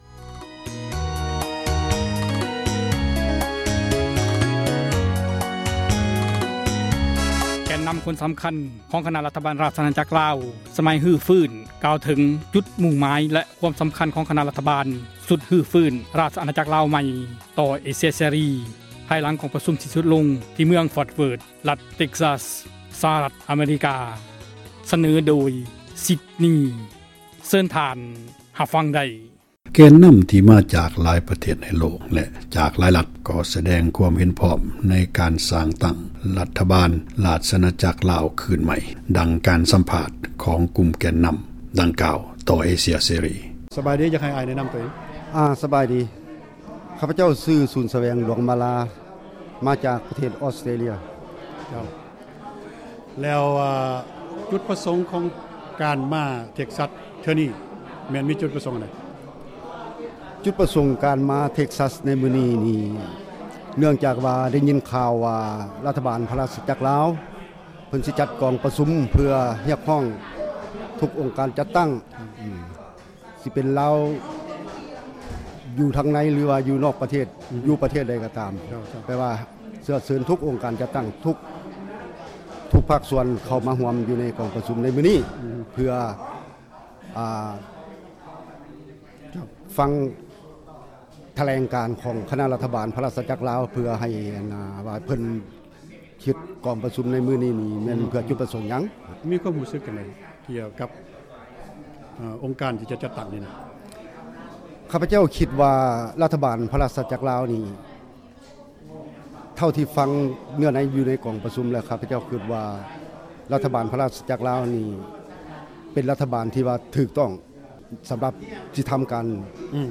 ສໍາພາດ ແກນນຳ ຄົນ ສຳຄັນ ຂອງ ຄນະ ຣັຖບານ ຣາຊອານາຈັກ ລາວ ສມັຍ ຮື້ຟື້ນ ປັບປຸງ ກ່າວເຖິງ ຈຸດ ມຸ້ງໝາຍ ແລະ ຄວາມ ສຳຄັນ ຕໍ່ ເອເຊັຽ ເສຣີ ພາຍຫລັງ ກອງ ປະຊູມ ສິ້ນສຸດ ລົງ ທິ່ ເມືອງ Fort Worth ຣັຖ Texas ສະຫະຣັດ ອະເມຣິກາ.